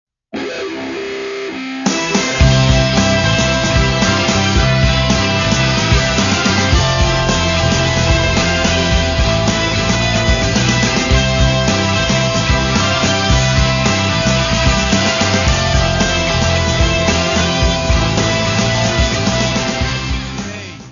Guitarra
Baixo
Bateria
Coro
Área:  Pop / Rock